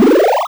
teleport.wav